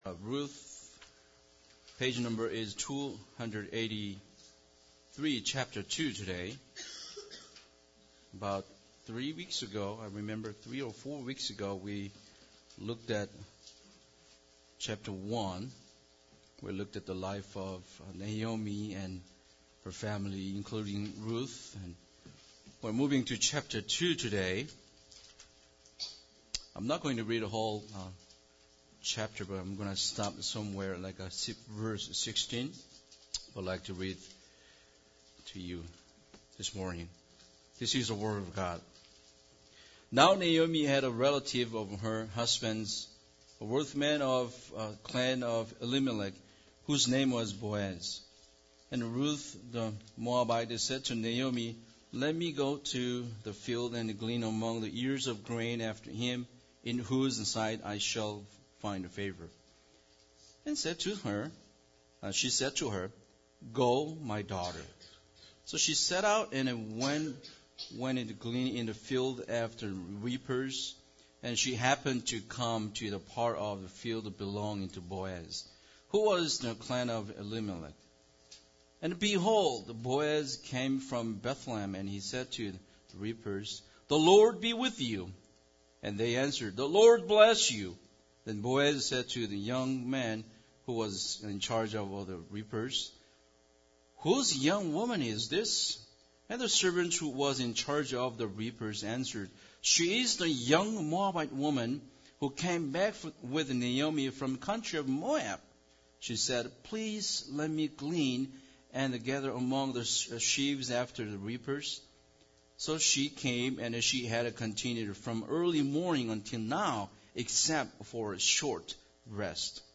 Passage: Ruth 2 Service Type: Sunday Service Bible Text